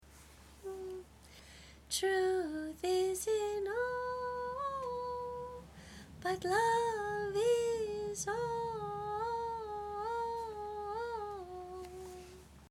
See individual song practice recordings below each score.
Many, especially the ones below the individual scores, were recorded on an old, portable cassette tape and have some distortion.